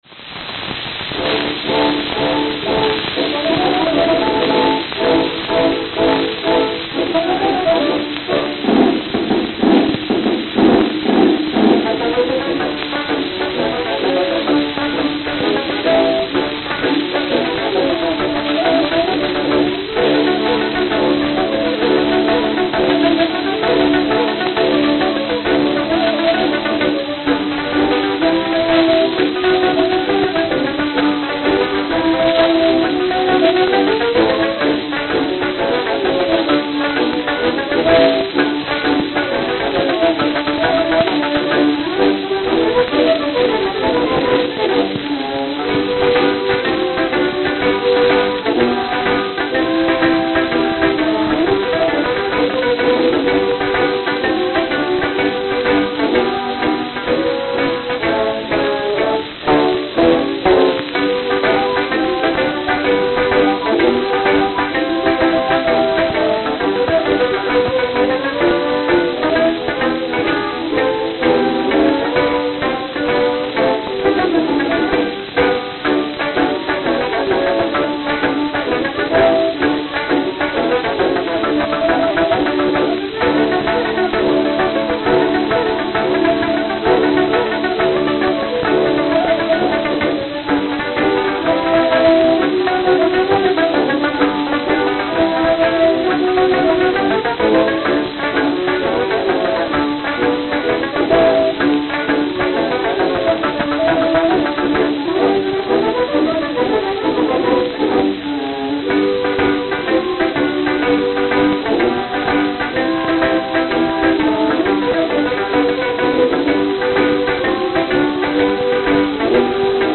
Philadelphia, Pennsylvania (?)
Note: Worn.